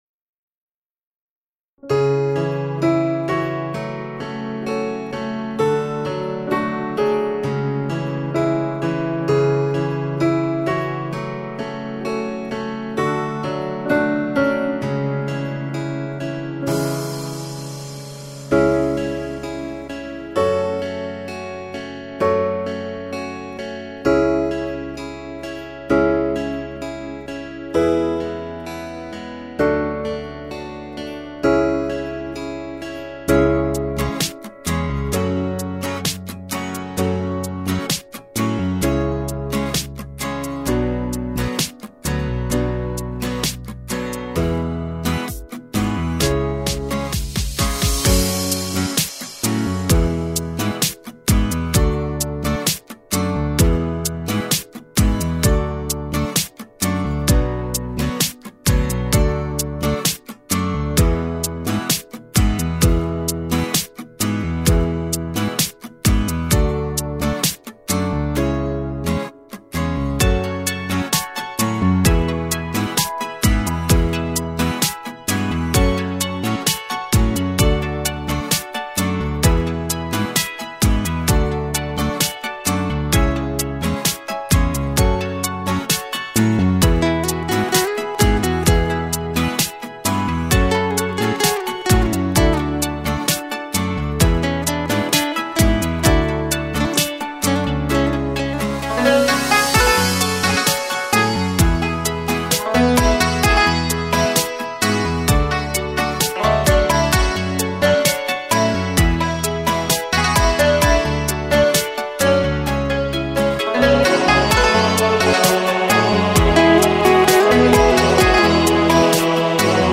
MP3 | بیکلامدانلود